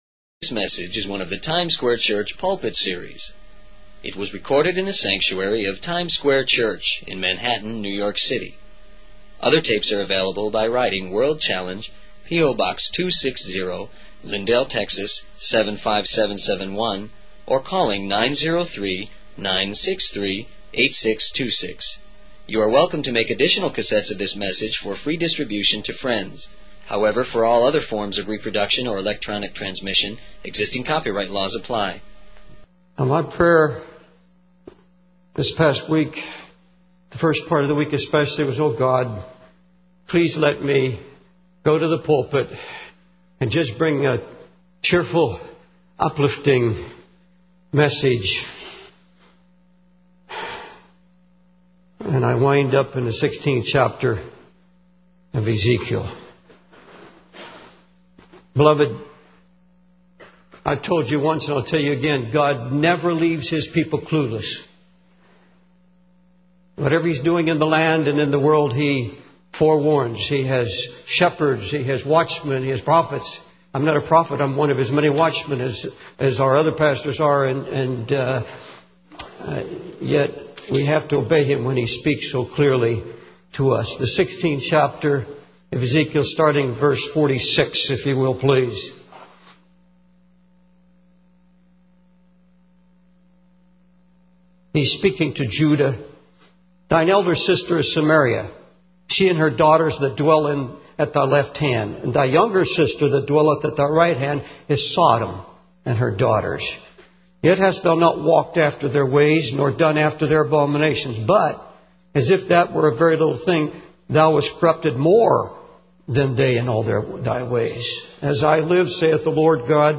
In this sermon, the speaker begins by expressing his initial desire to bring a cheerful and uplifting message, but instead finds himself drawn to the 16th chapter of Ezekiel. He emphasizes that God never leaves his people clueless and promises to protect and deliver them from the power of sin and the devil. The speaker then discusses the incredible offer of freedom through Jesus Christ and how it is often rejected by people.